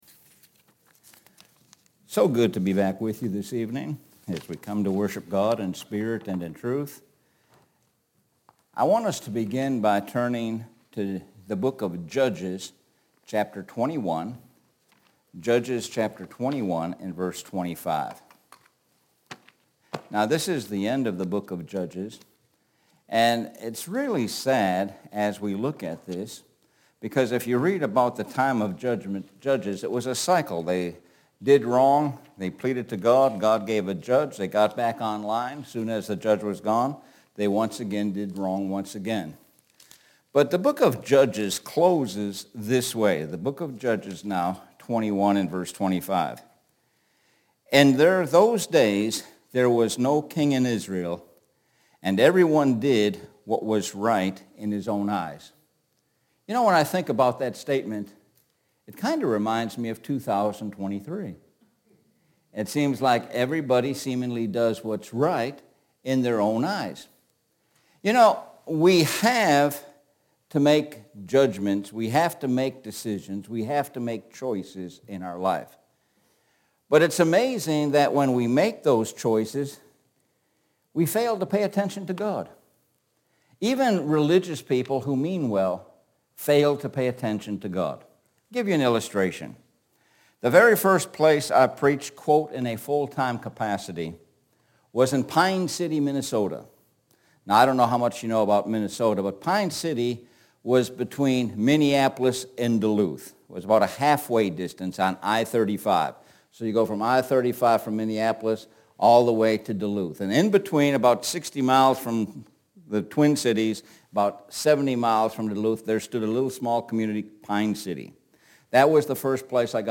Sun PM Sermon – Able to discern